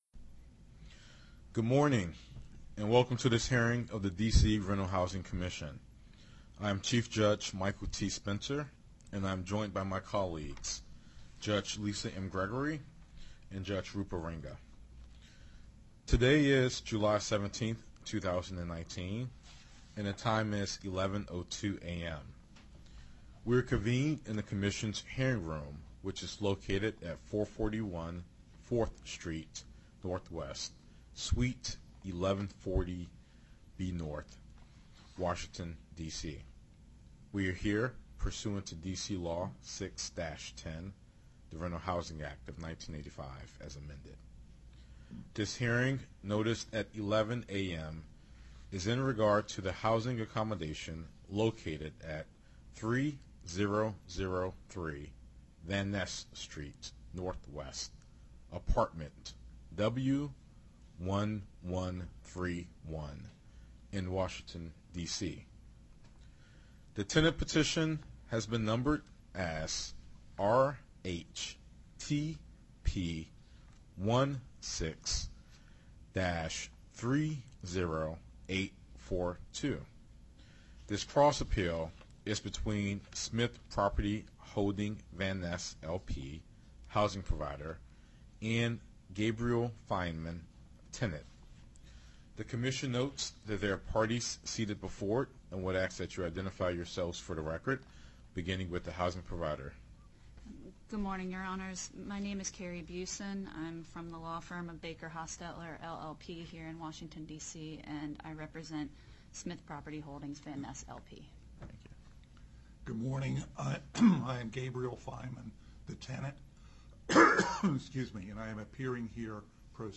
Hearing before the RHC 2019-07-17 (Recording - 40M)